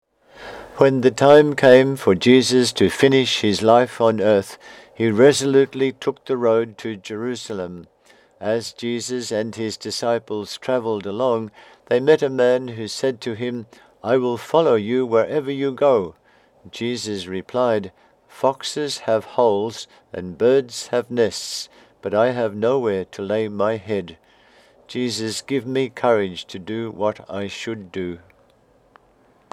My recording of this reading